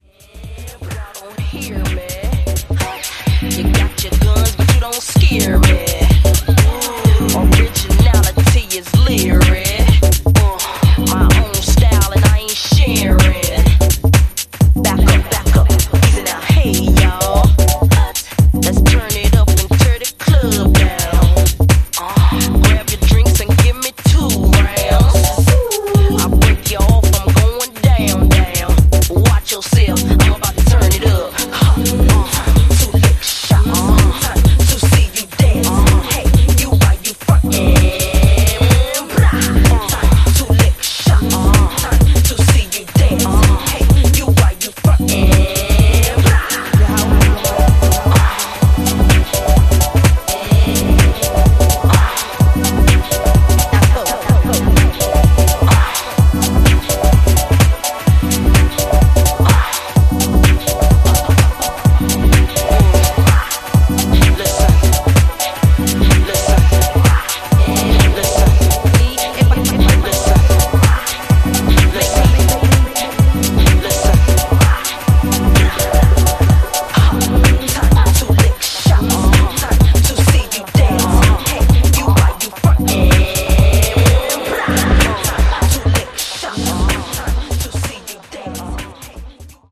simple MPC work out
with a bouncy bassline and deep chords.